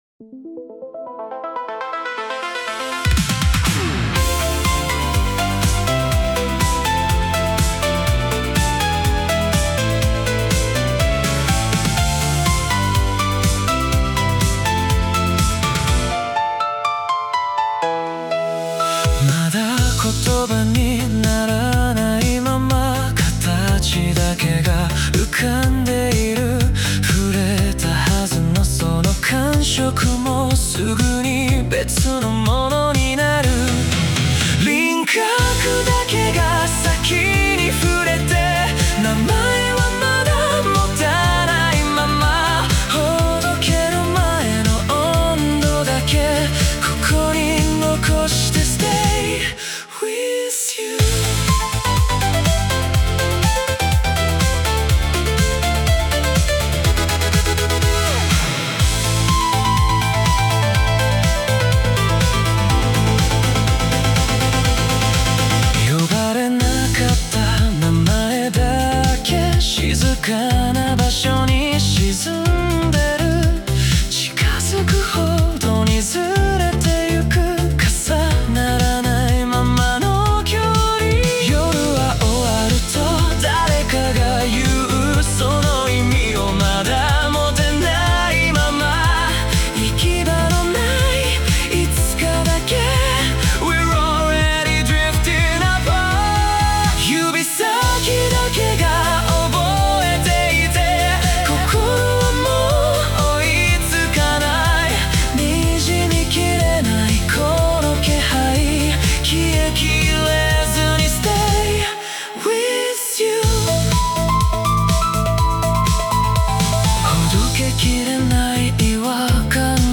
男性ボーカル
イメージ：シンセポップ,120BPM,Aマイナー,メランコリック,アトモスフェリック,切ない,男性ボーカル,